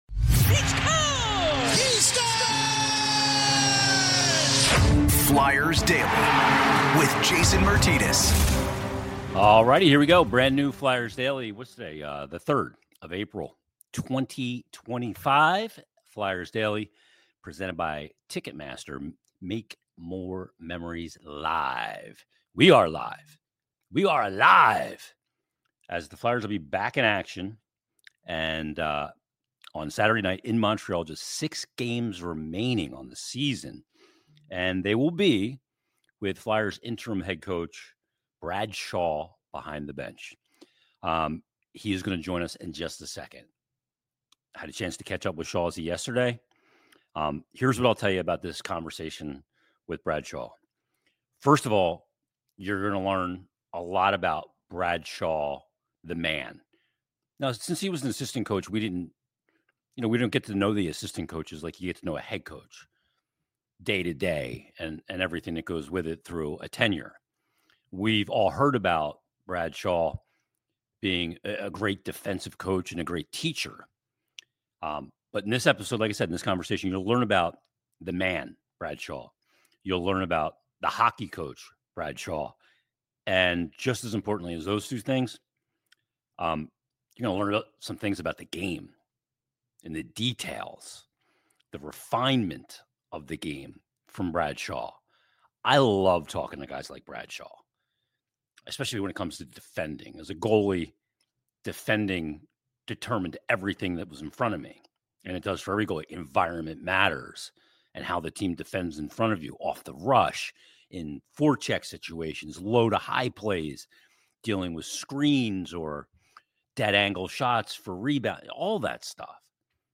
in depth conversation